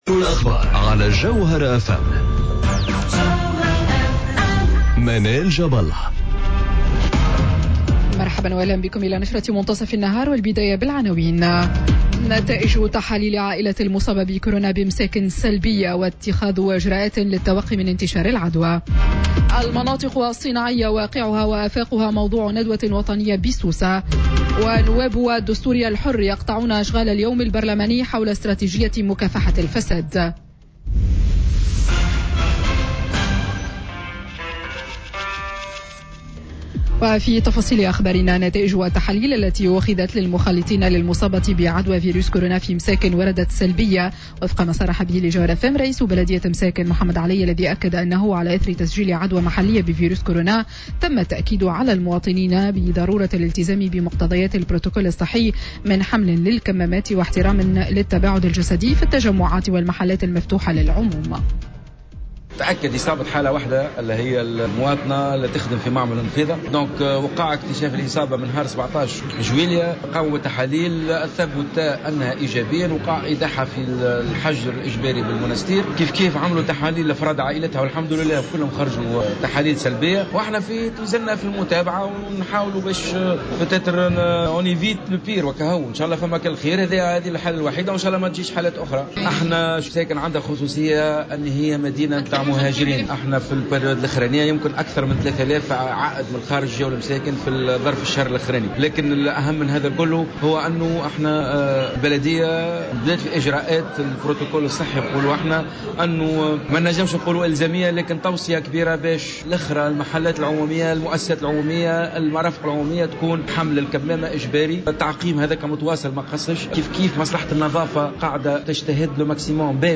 نشرة أخبار منتصف النهار ليوم الإثنين 20 جويلية 2020